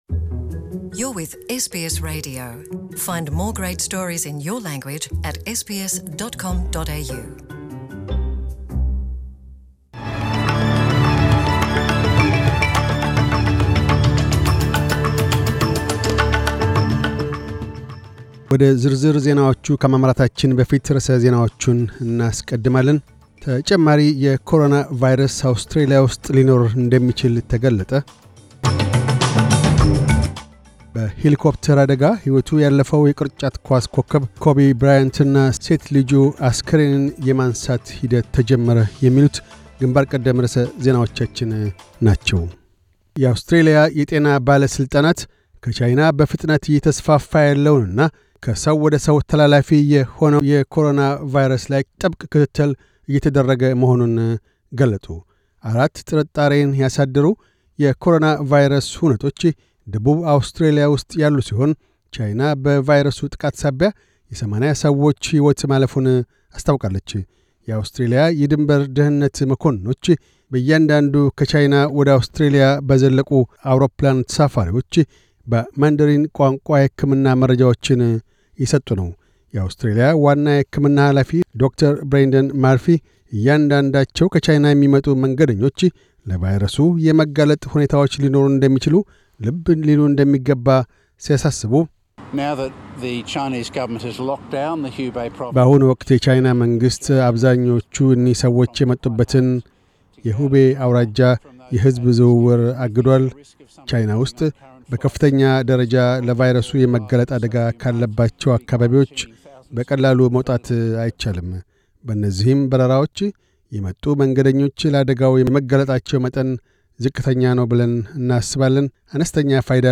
News Bulletin 2711